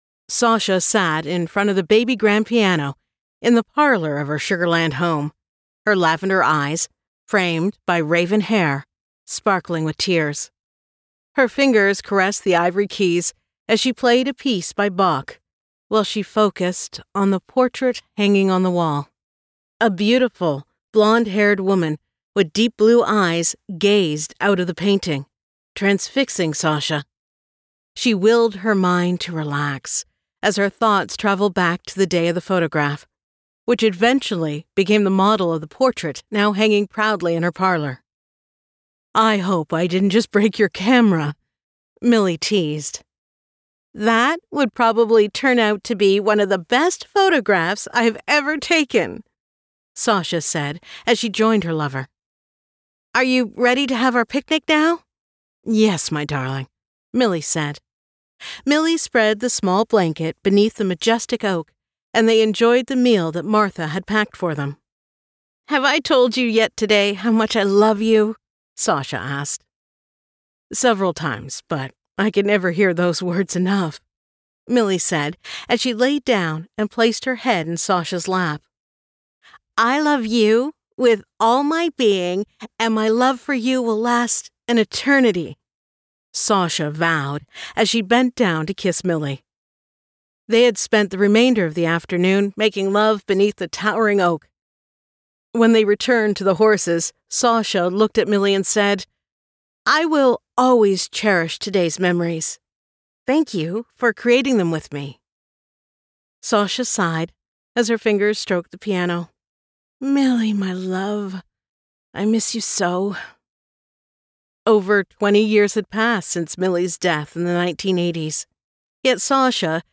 Sugarland by Ali Spooner [Audiobook]